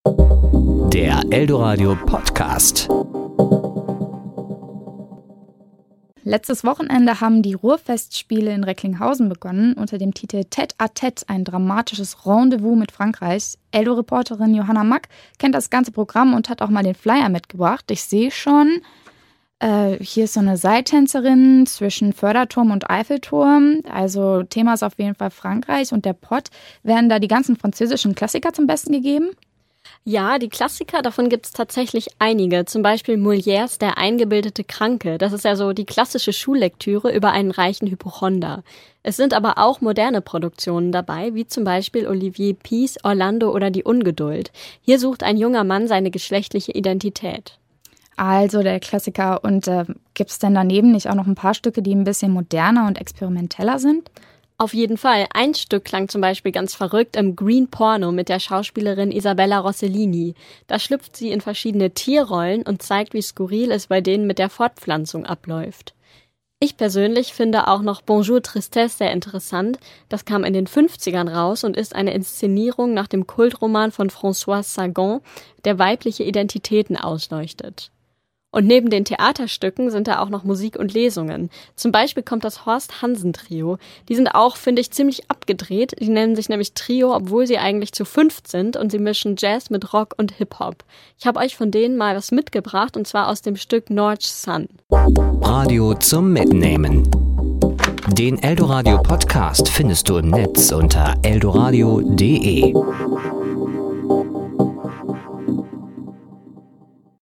Kollegengespräch  Sendung